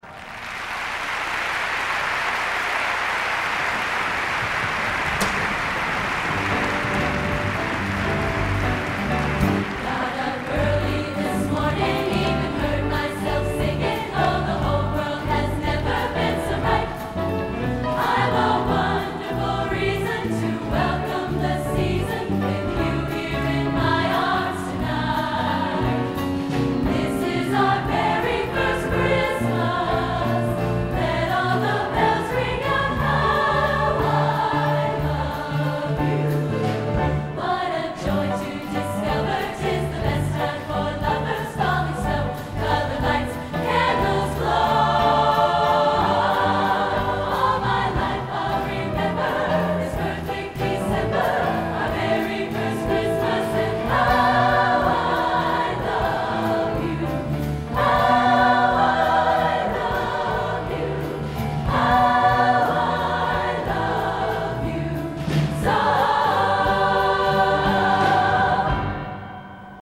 Collection: Christmas Show 1994
Location: West Lafayette, Indiana
Genre: | Type: Christmas Show |